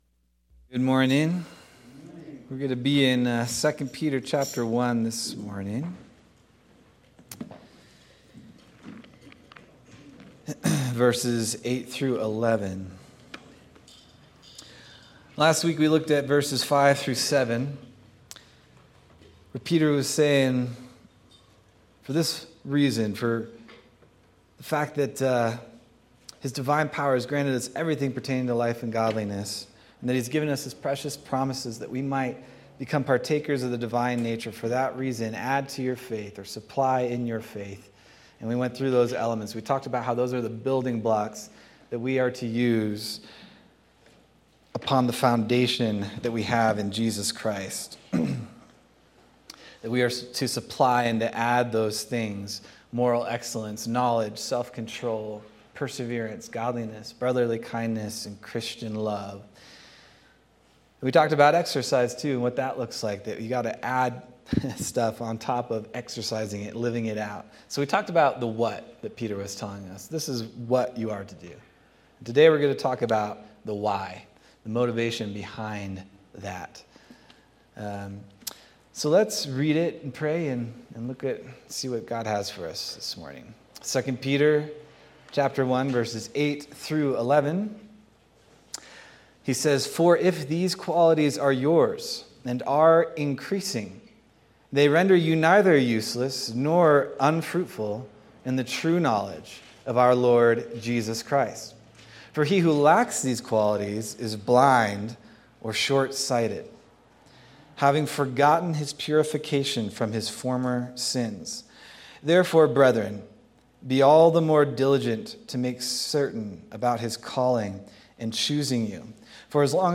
September 28th, 2025 Sermon